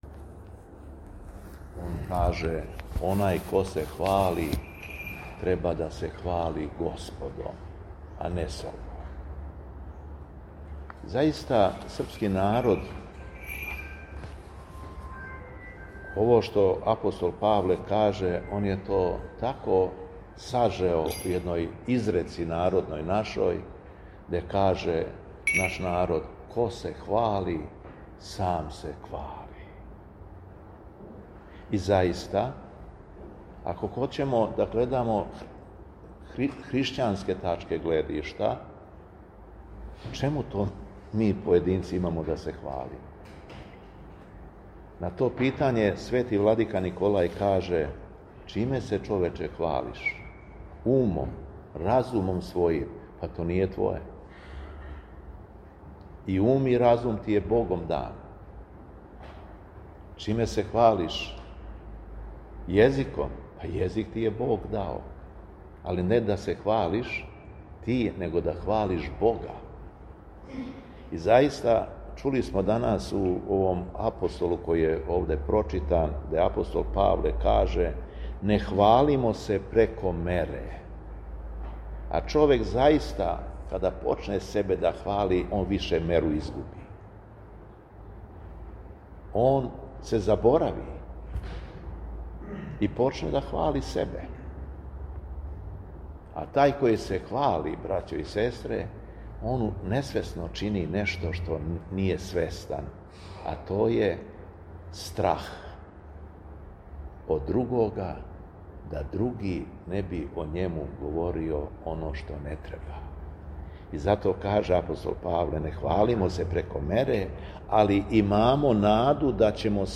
У тринаести четвртак по Духовима 4. септембра 2025. године, Његово Високопресвештенство Митрополит шумадијски Г. Јован служио је Свету Литургију у Старој Цркви у Крагујевцу уз саслужење братства овога светога храма.
Беседа Његовог Високопреосвештенства Митрополита шумадијског г. Јована
Беседом се верном народу обратио Високопреосвећени Митрополит Јован, рекавши да: